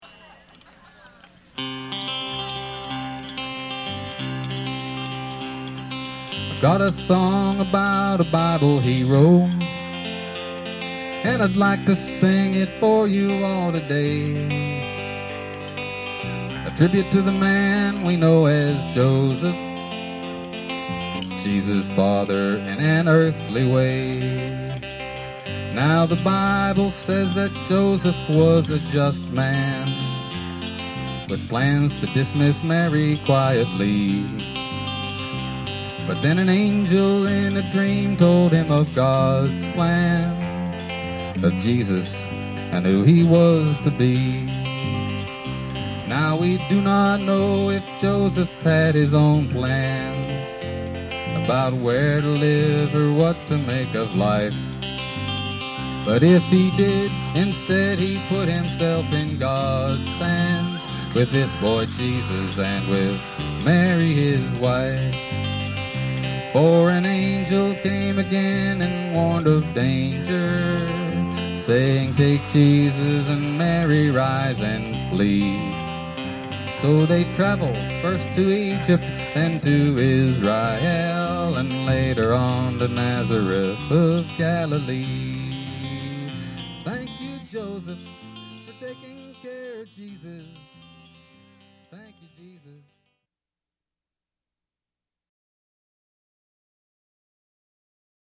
Thank You Joseph (live at the BAM Picnic)    Download MP3